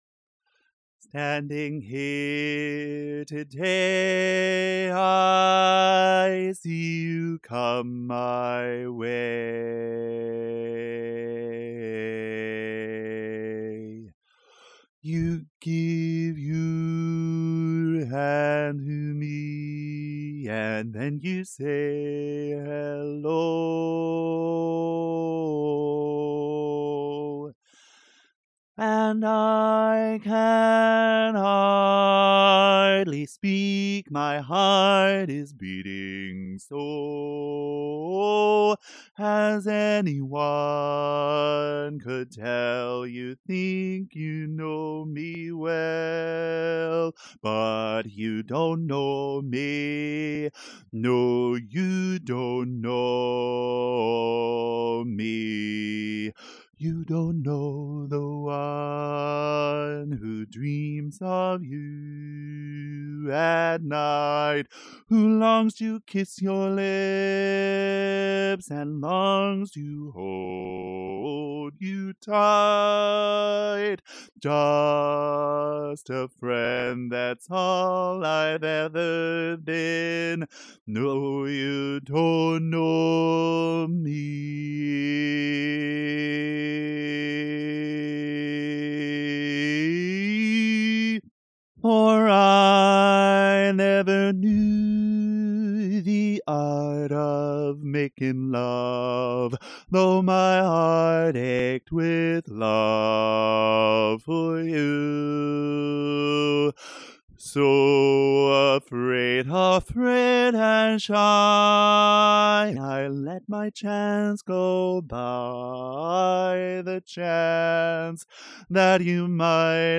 Harmony ReChoired (chorus)
Ballad
Barbershop
D Major
Bass